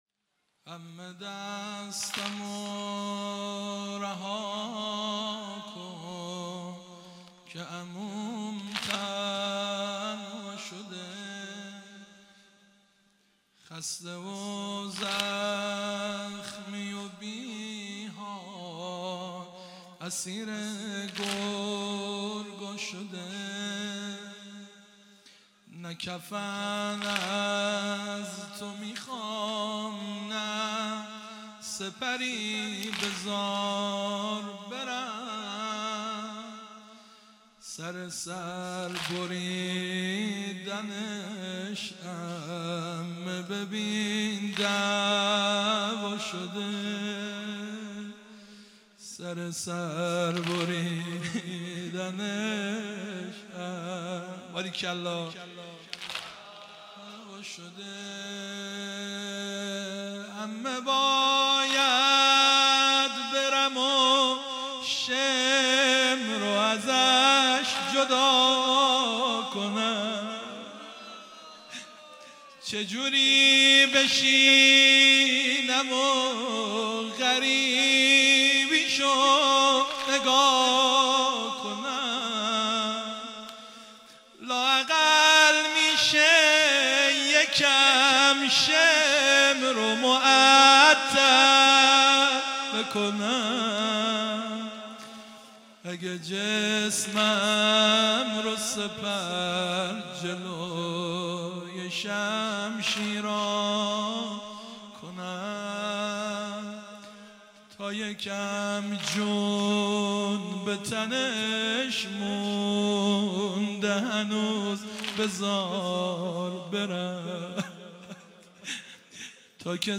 واحد سنگین | عمه دستمو رها کن
مداحی
شب پنجم محرم 1441 | مسجد ملا اسماعیل